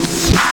13 REV CLP-R.wav